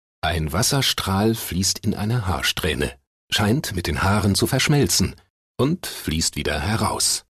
dunkel, sonor, souverän
Mittel plus (35-65)
Comment (Kommentar)